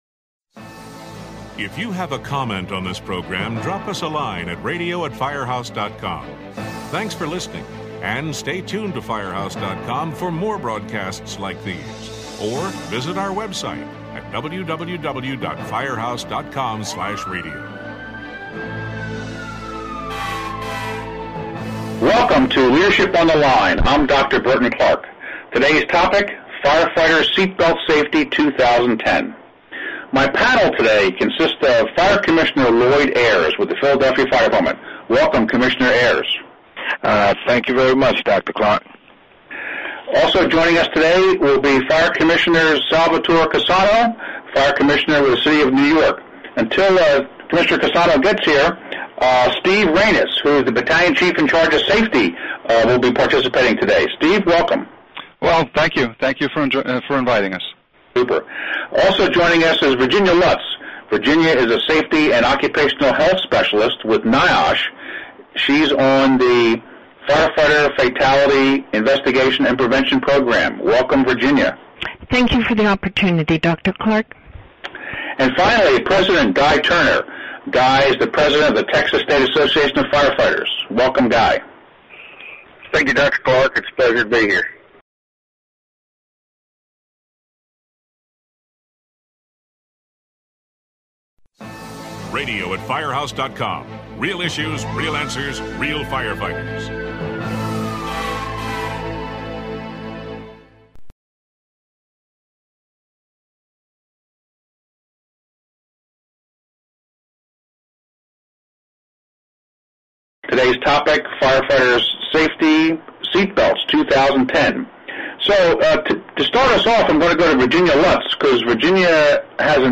The panelists discuss their efforts and challenges while trying to incorporate the National Fire Service Seat Belt Pledge Campaign.